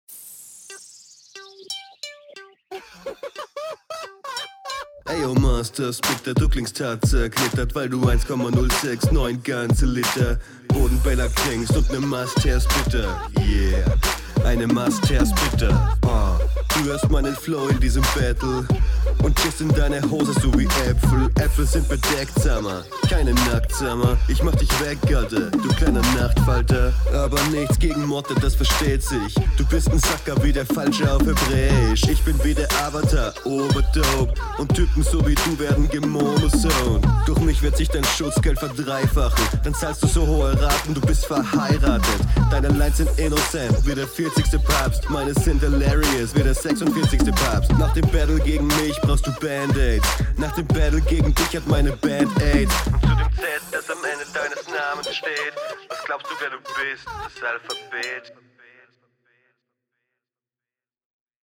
Verhigh-ratet hat mich gekillt hahaha Beat ist stupid aber ganz funny (wenn auch minimal nervig) …